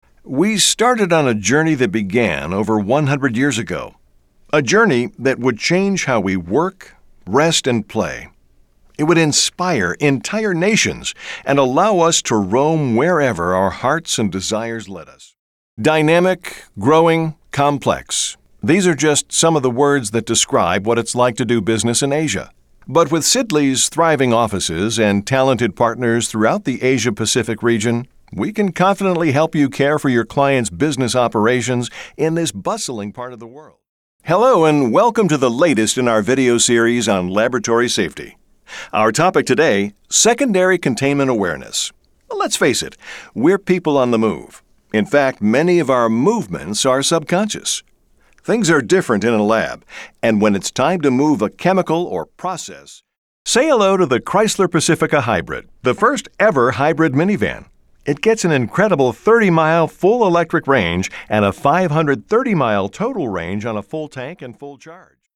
Industrial VO Sample
Middle Aged
Have been doing voice-work from my Home Studio for well over 20 years and I really love what I do... From Commercials and Industrial Narrations to Audiobooks... it's always interesting...